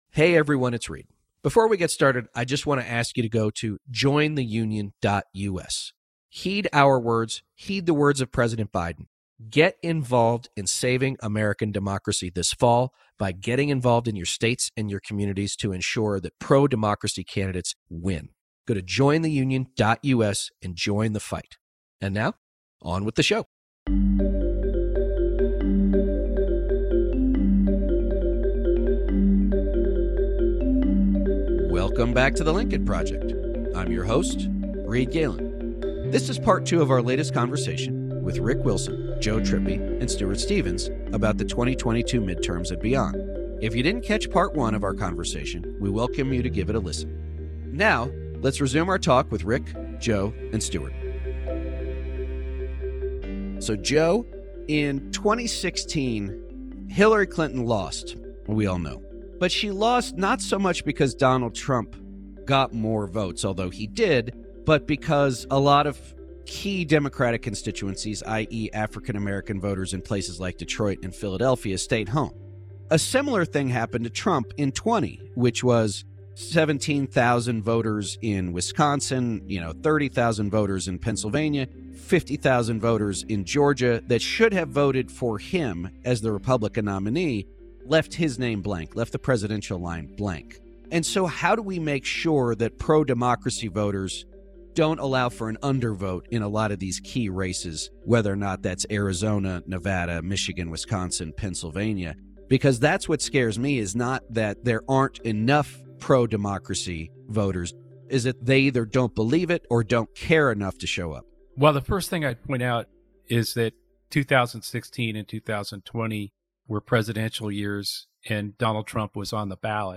Host Reed Galen is joined by fellow Lincoln Project Co-Founder Rick Wilson and Lincoln Project Senior Advisors Stuart Stevens and Joe Trippi for the conclusion to their conversation of how the political landscape is unfolding less than 2 months out from election day.